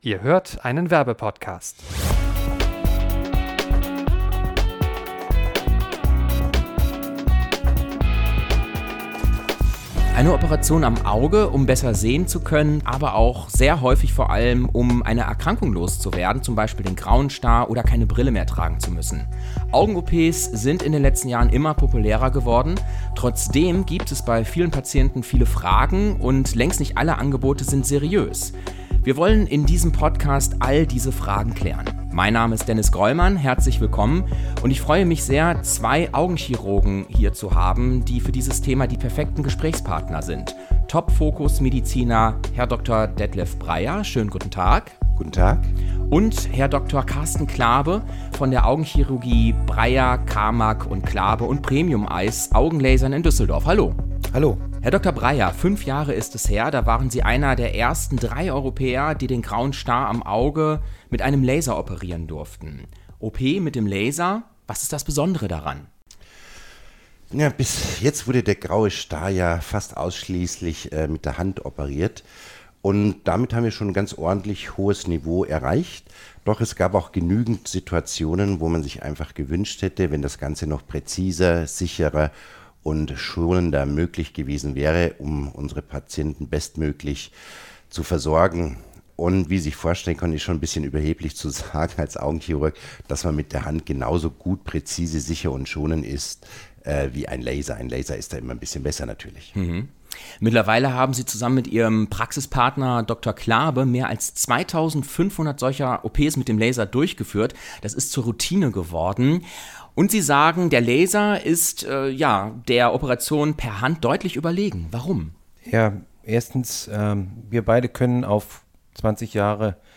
Experteninterview: Was ihr zum Thema Augenlasern wissen solltet (Anzeige) ~ Trip-Tipps für 9 Podcast